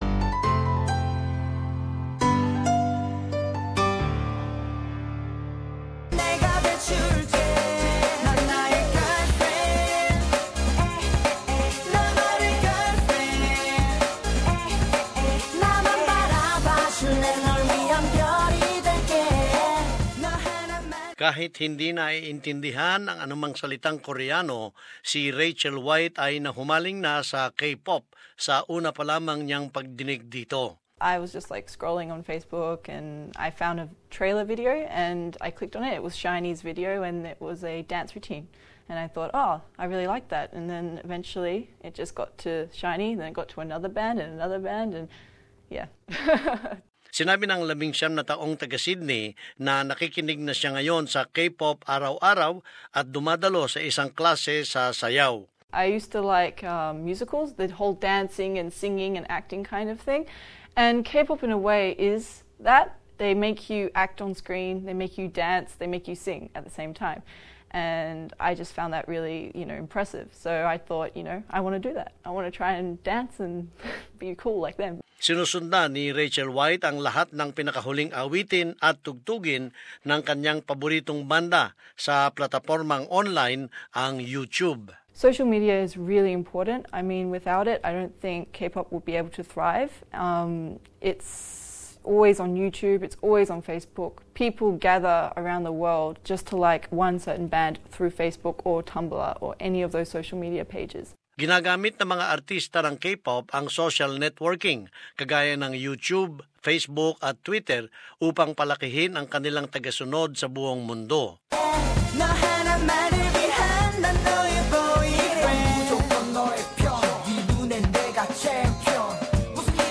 As this report shows, popular acts are going global - including at music events in Australia, the United States and the Middle East.